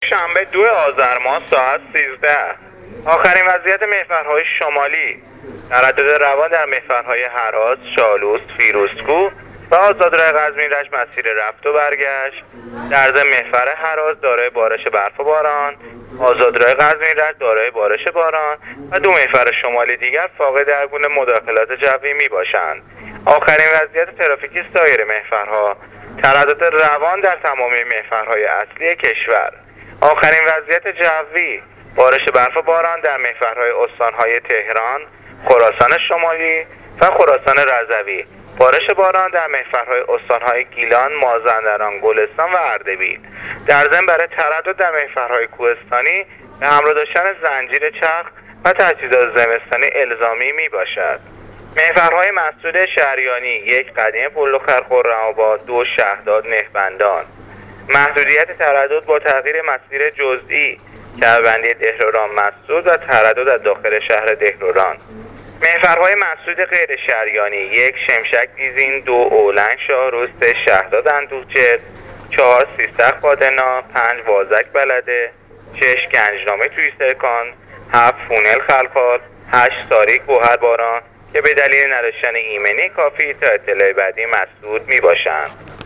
گزارش رادیو اینترنتی وزارت راه و شهرسازی از آخرین وضعیت ترافیکی جاده‌های کشور تا ساعت ۱۳ دوم آذرماه ۱۳۹۸/ تردد عادی و روان در همه محورهای کشور / بارش باران در مسیرهای ۷ استان